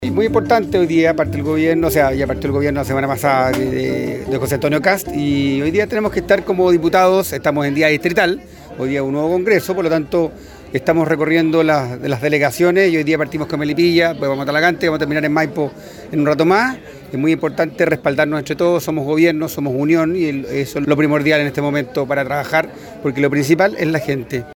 DIPUTADO-DIEGO-VERGARA-NUEVO-DELEGADO.mp3.mp3